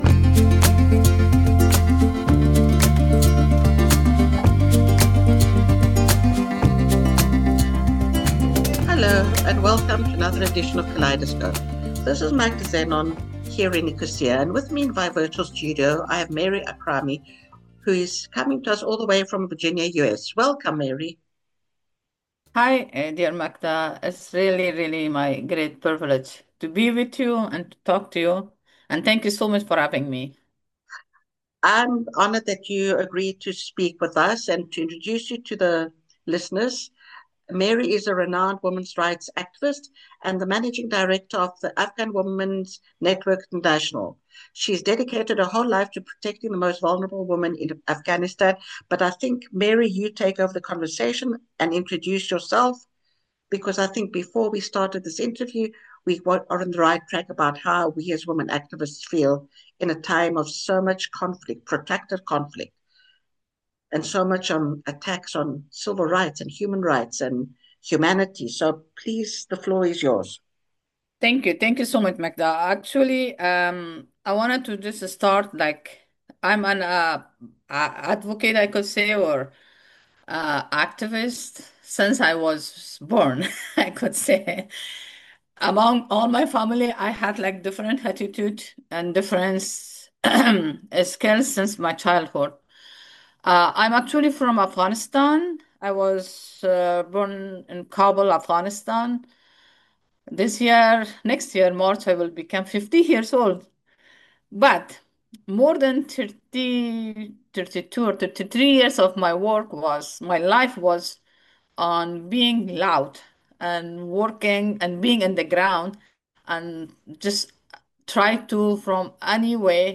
speaks with despair, vulnerability and realism